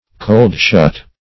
Search Result for " cold-shut" : The Collaborative International Dictionary of English v.0.48: Cold-shut \Cold"-shut`\, a. (Metal.) Closed while too cold to become thoroughly welded; -- said of a forging or casting.